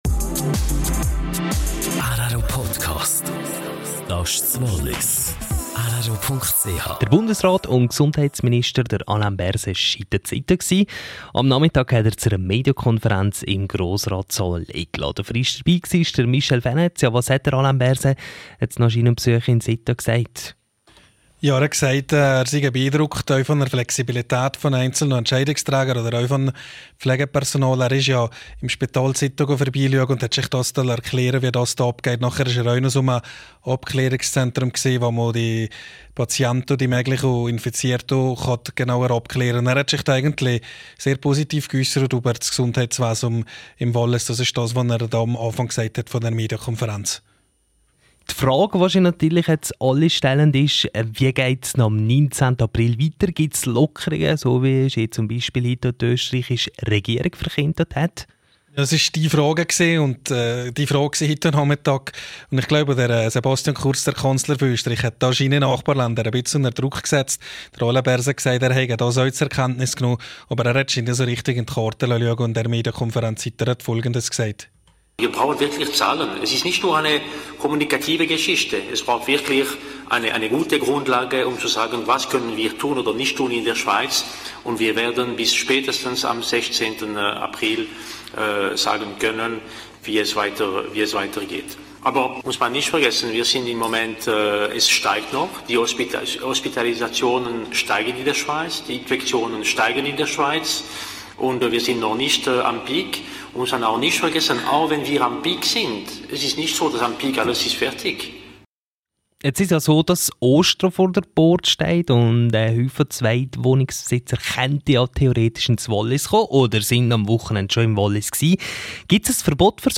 Bundesrat Alain Berset an der Medienkonferenz in Sitten am Montagmittag.